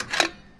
fuellinereplace.wav